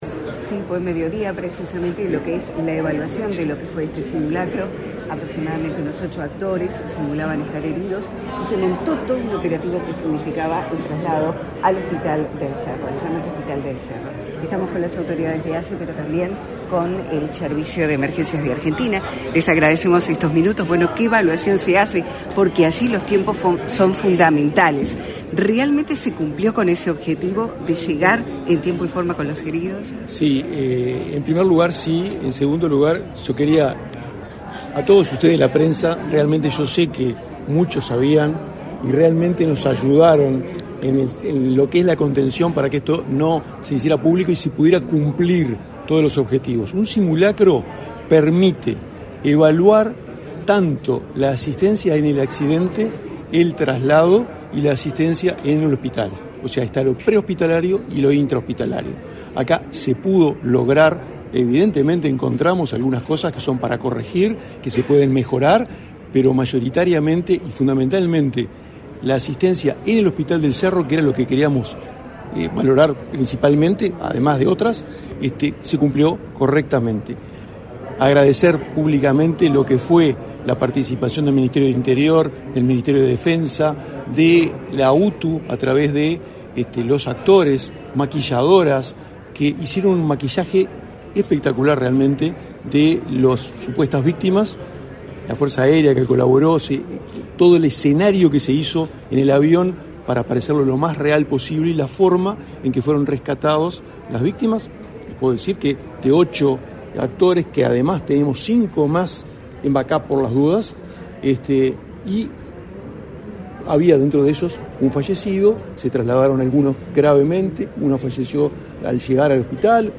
Declaraciones a la prensa del gerente general de ASSE, Eduardo Henderson, y del director general del SAME Buenos Aires, Alberto Crescenti